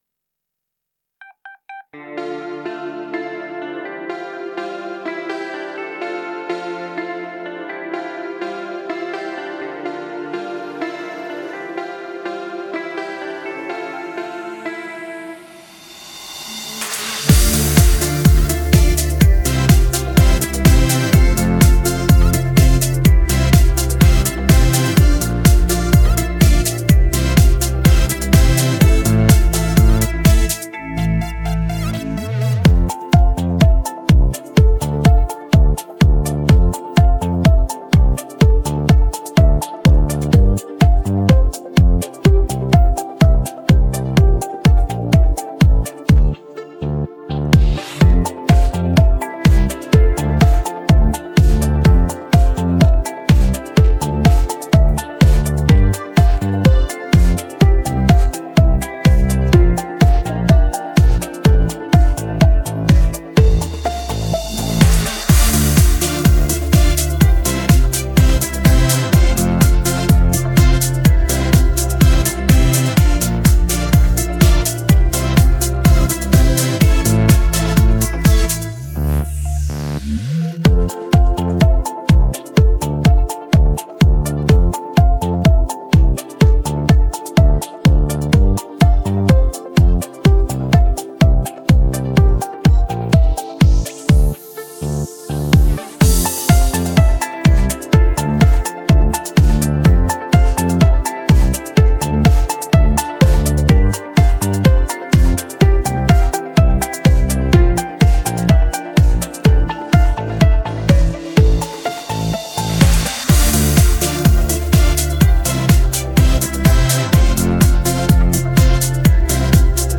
Детская песня